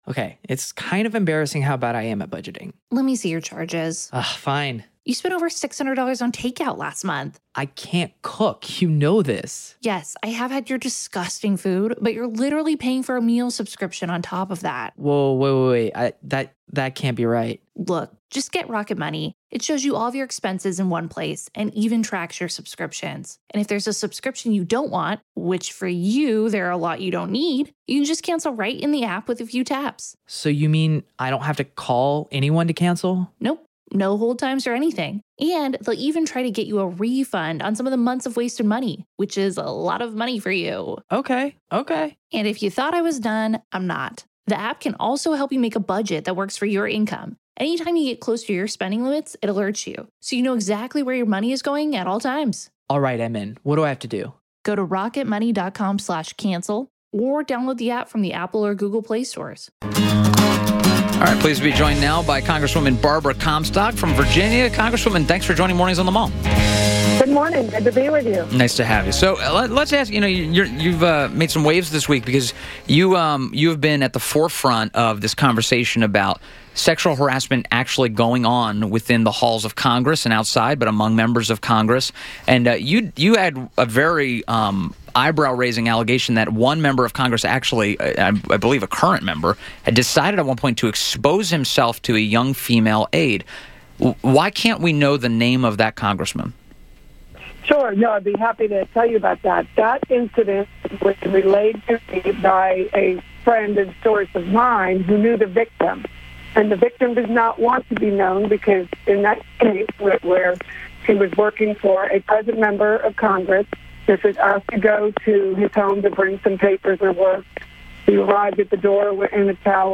INTERVIEW – REP. BARBARA COMSTOCK – R-VA – discussed sexual harassment on Capitol Hill.